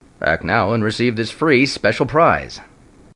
混音过程采用了严格的Logic Pro X插件（例如压缩器，均衡器）。
标签： 广告 商业 电视
声道立体声